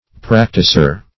Practisour \Prac"ti*sour\, n. A practitioner.